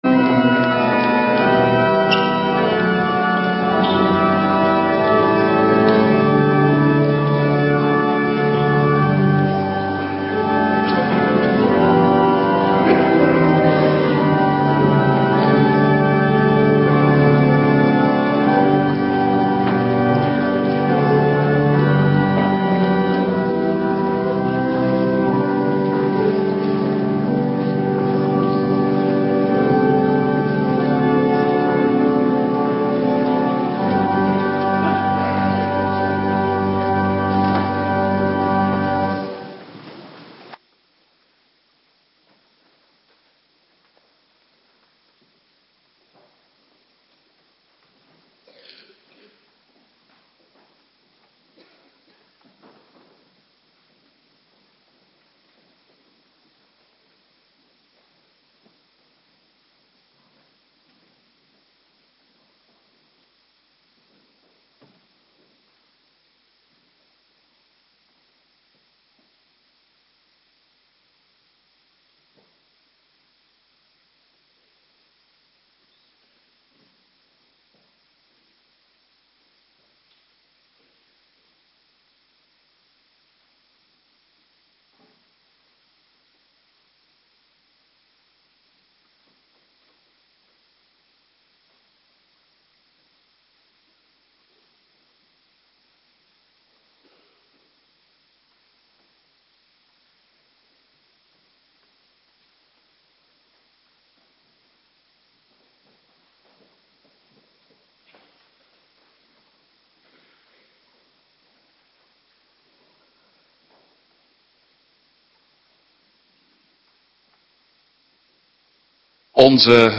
Dankdag Morgendienst